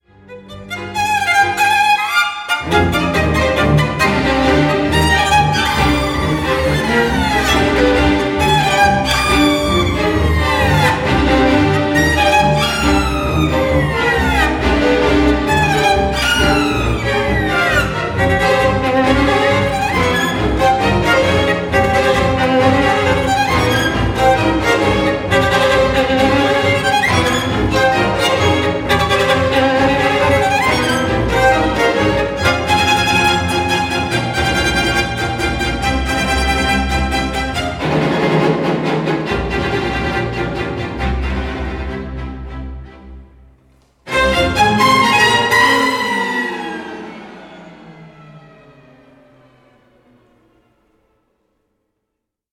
Hybrid Stereo/Multichannel 5.1
violin